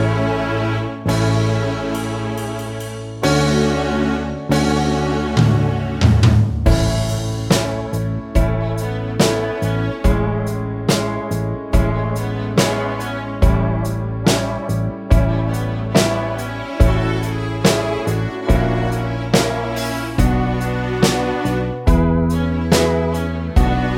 no strings Pop (1970s) 3:51 Buy £1.50